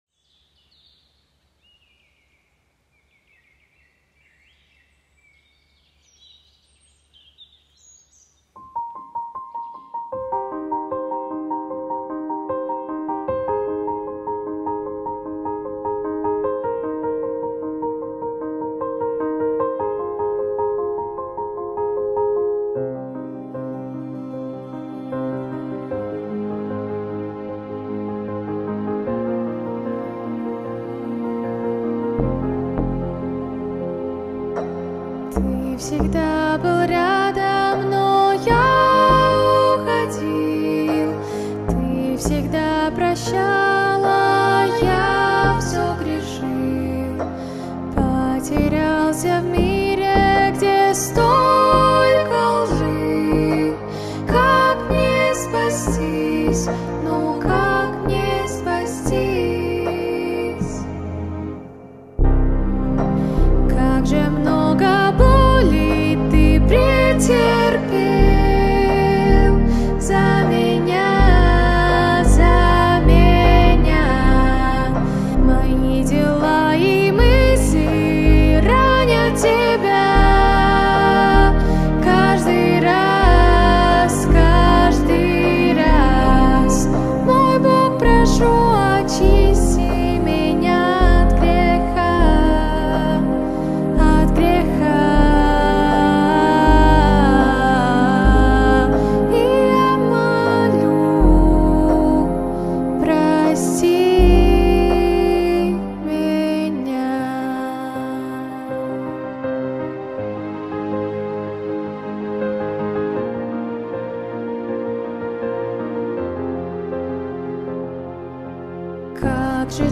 песня
776 просмотров 258 прослушиваний 59 скачиваний BPM: 79